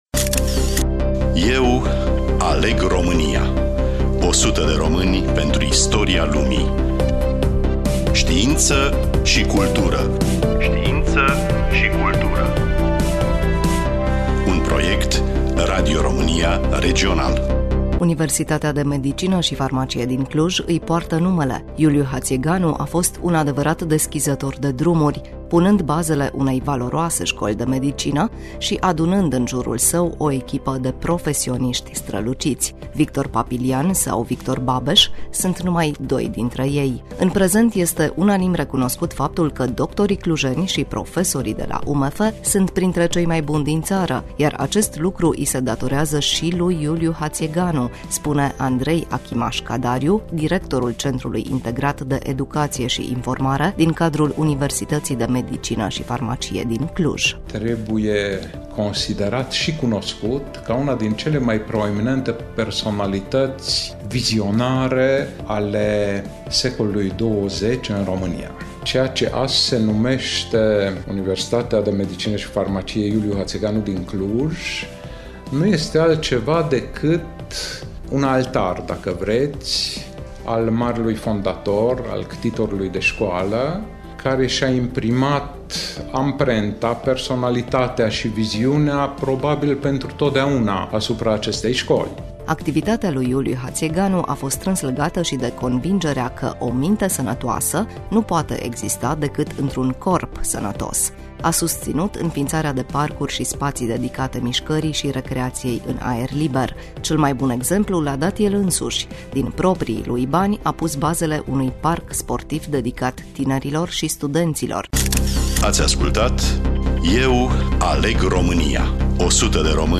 Studioul: Radio România Cluj